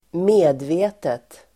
Uttal: [²m'e:dve:tet]